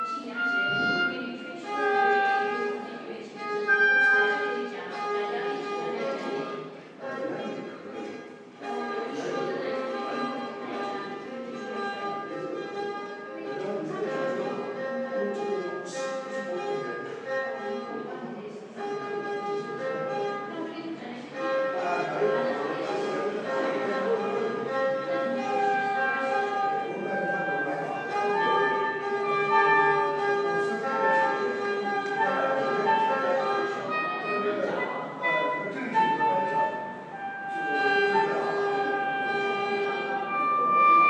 Chinese Taoist musicians getting ready for a concert at BOZAR in Brussels
Sheng er hu